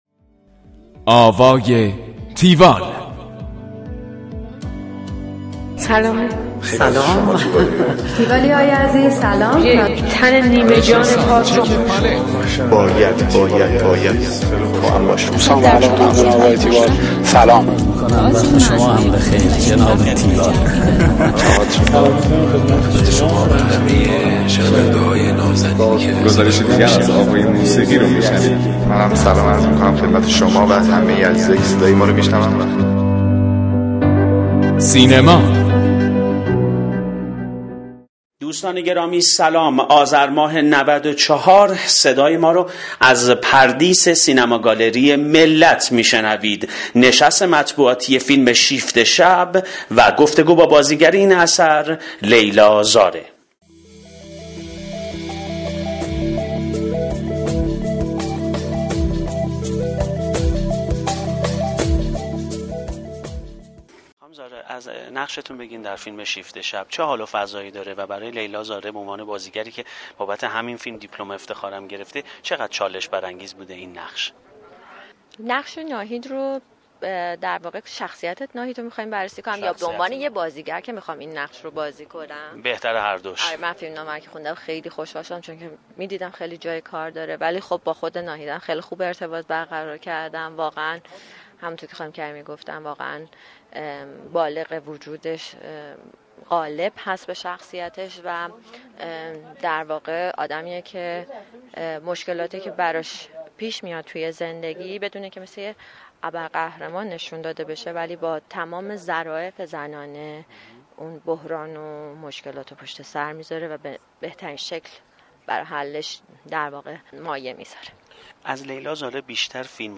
گفتگوی تیوال با لیلا زارع
tiwall-interview-leilazare.mp3